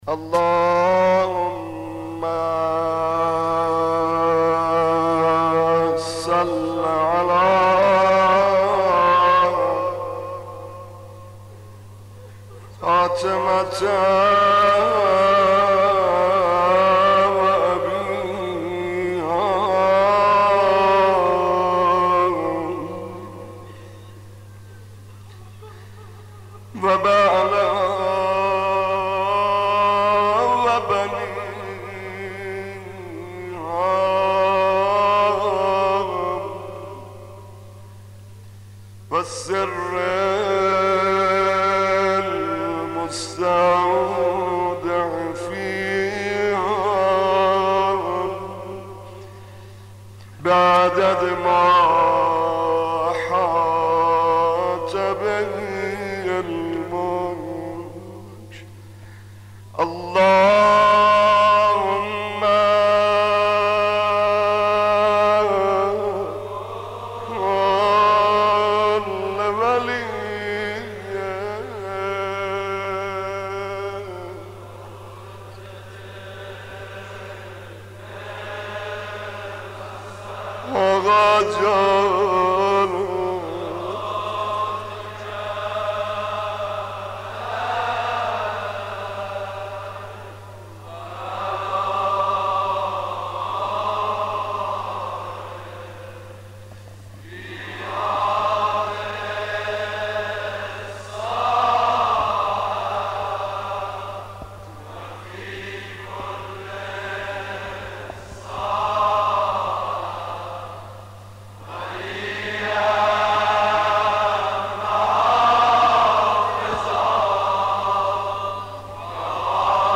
مناسبت : شب هشتم محرم